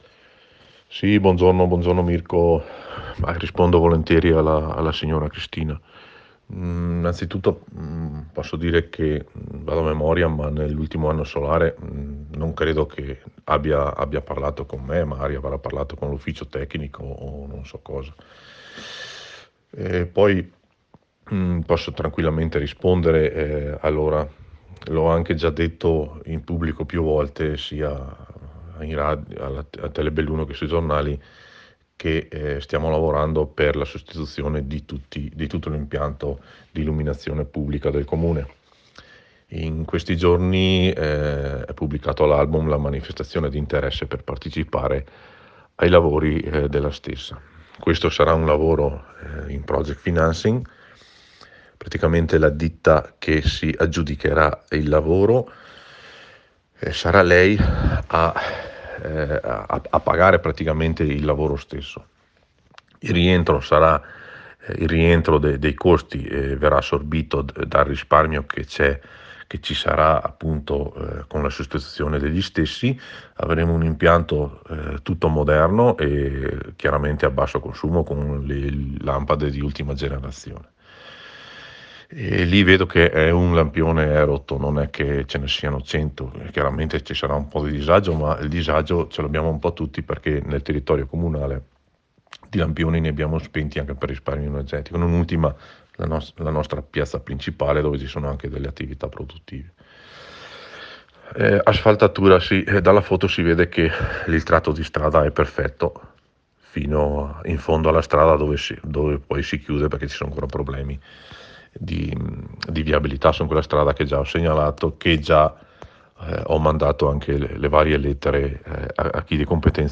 IERI ALLA RADIO
MORENO DE VAL, SINDACO DI SAN TOMASO